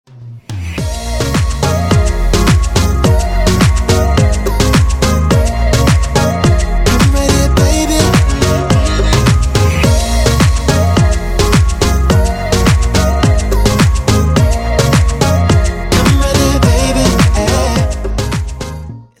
поп
позитивные
dance
RnB
house
Позитивный и бодрый рингтон